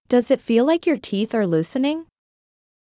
ﾀﾞｽﾞ ｲｯﾄ ﾌｨｰﾙ ﾗｲｸ ﾕｱ ﾃｨｰｽ ｱｰ ﾙｰｽﾆﾝｸﾞ